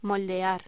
Locución: Moldear
voz